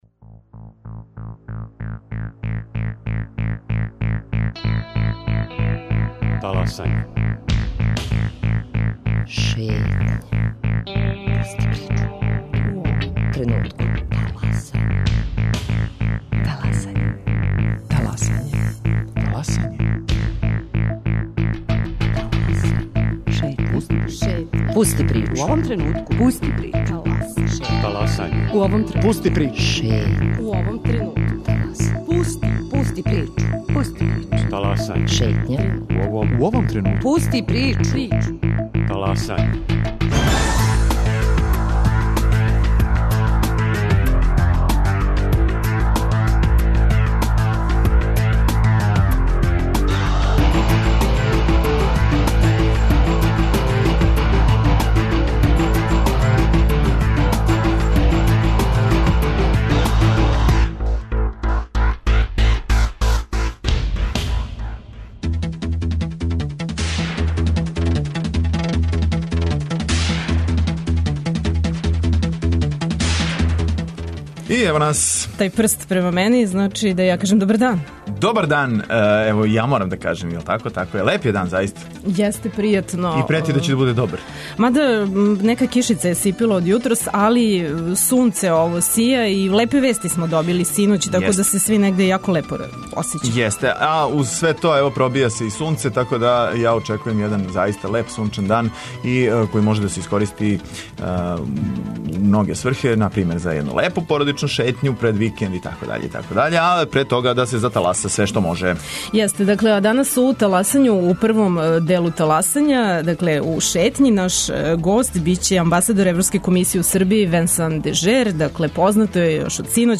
Гост Радио Београда 1 је амбасадор Европске Комисије у Србији Венсан Дежер. Сазнаћете како ће се добијање статуса кандидата за учлањење у Европску унију политички одразити на Србију, регион и Европску унију, а шта ће конкретно значити за живот грађана Србије? Који су услови да добијемо датум за почетак приступних преговора?